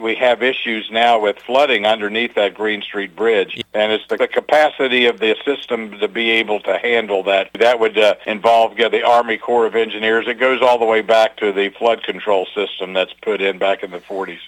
Planned re-decking of Interstate 68 would entail a detour of truck traffic passing under the rail bridge at Greene Street. Mayor Ray Morriss says the bridge has to go up – there are too many issues to dig down…